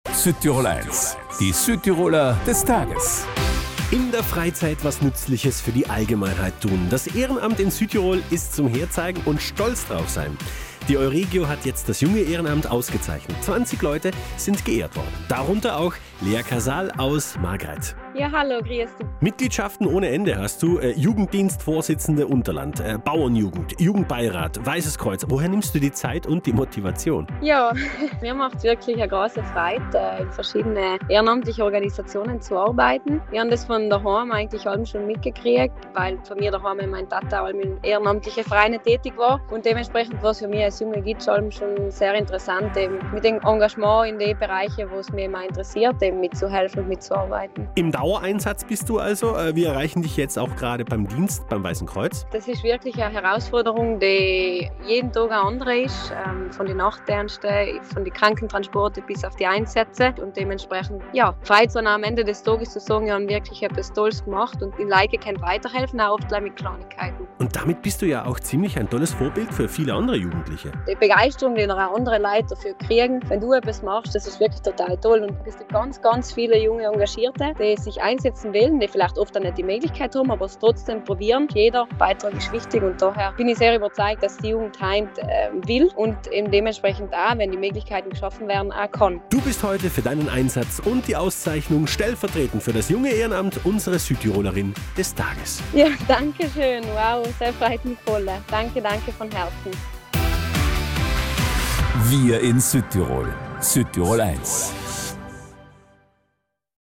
Im Interview erzählt sie, woher sie die Motivation für diesen Dauereinsatz nimmt, wie sich ihr Alltag im Ehrenamt gestaltet und ob ihr bewusst ist, dass sie für viele Jugendliche ein echtes Vorbild ist.